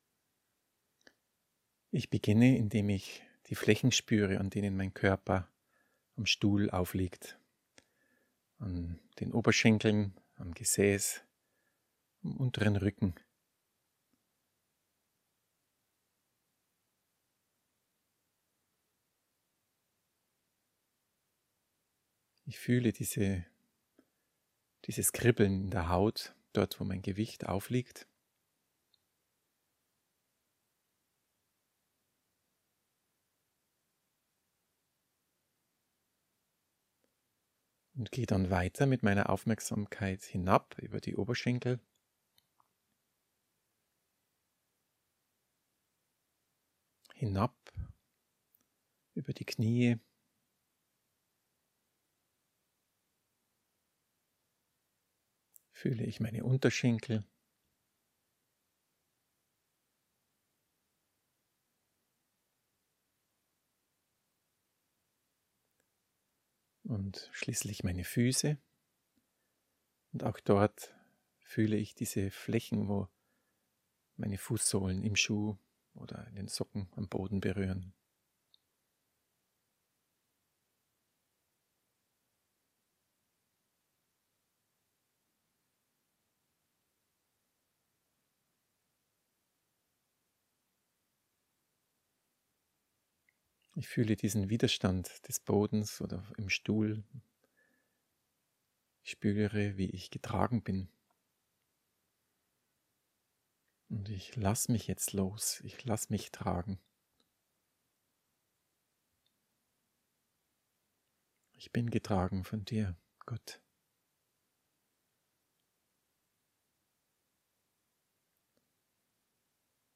Audio Anleitung
Hinfuehrung-ins-Gebet.mp3